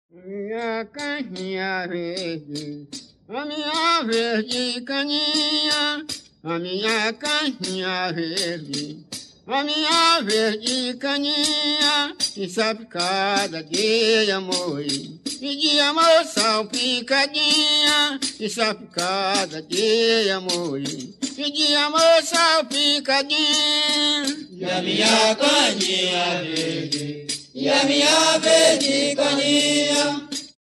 Cada vez que se defrontam, dão uma batida de palmas.
Cana-verde
autor: Grupo de cana-verde, título: 1975.